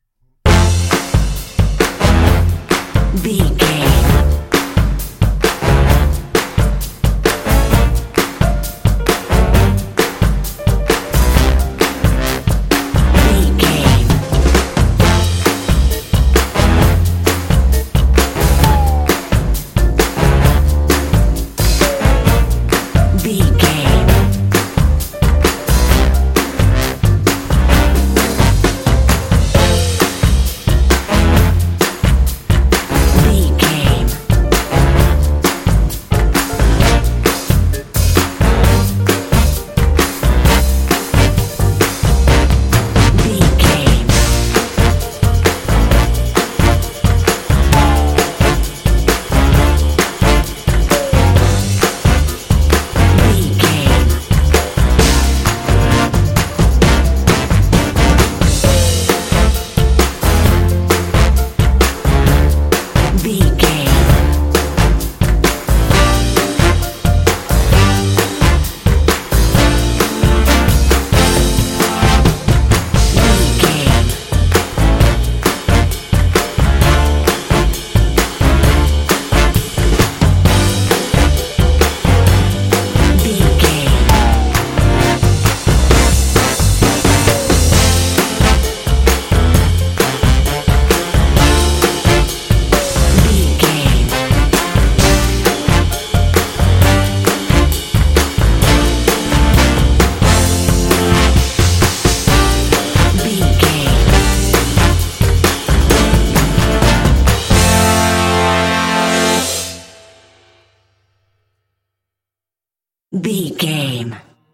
Uplifting
Ionian/Major
happy
bouncy
groovy
drums
brass
electric guitar
bass guitar